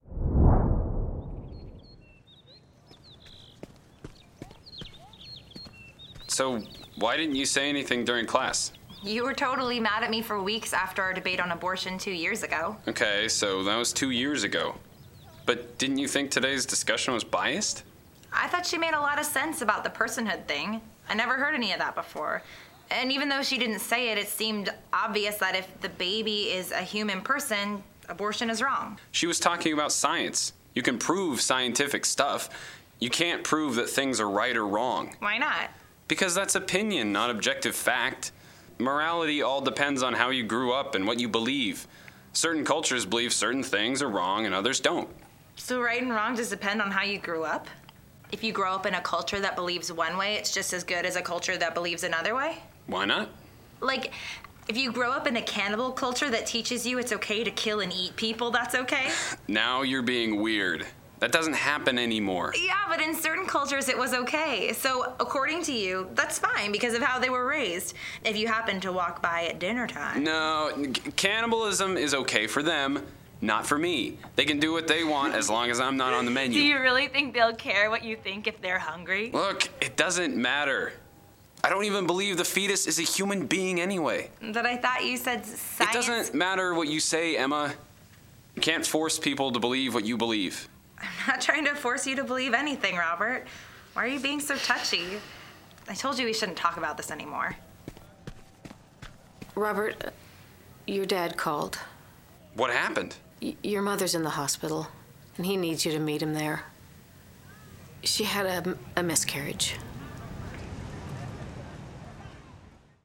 A professional audio drama production of Robert and Emma. 2 hours on 4 audio CDs, featuring a cast of 14 voice actors.